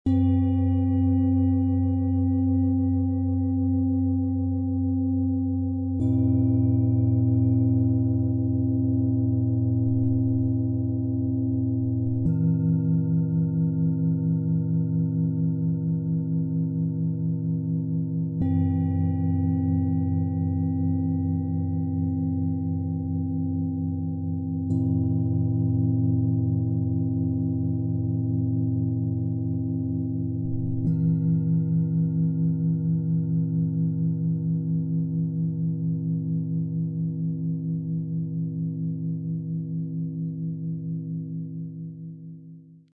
Dieses Set vereint ruhige Entfaltung, kraftvolle Sanftheit und emotionale Tiefe zu einem harmonischen Erlebnis voller innerer Klarheit.
Tiefster Ton: Jupiter und Lilith – Entfaltung und innere Stärke
Mittlerer Ton: Mond und Hopi – Geborgenheit und Seelenruhe
Höchster Ton: Hopi – Herzensöffnung und Selbstliebe
Im Sound-Player - Jetzt reinhören können Sie den Originalklang genau dieser Klangschalen des Sets anhören und sich von ihrer beruhigenden Schwingung inspirieren lassen.
Bengalen Schale, Matt